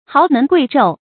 豪门贵胄 háo mén guì zhòu 成语解释 指权们贵族的子孙。